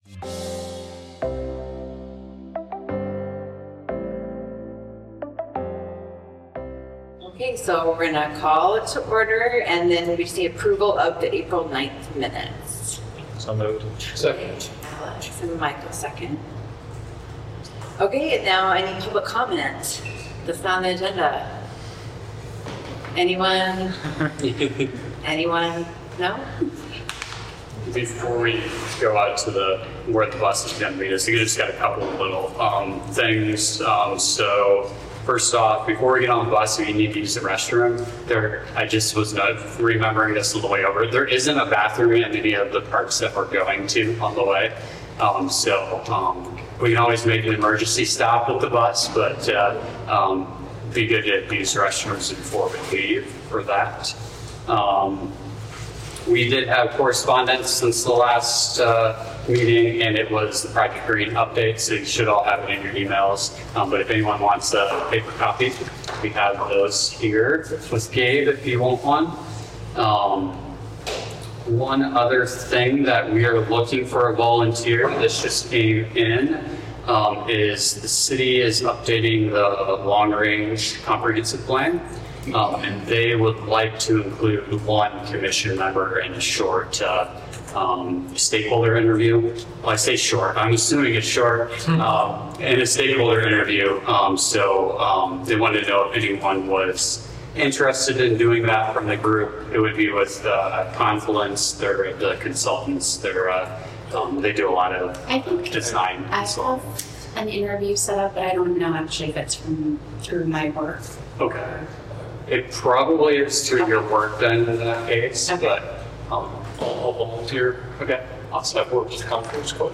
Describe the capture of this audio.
A meeting of the City of Iowa City's Parks and Recreation Commission.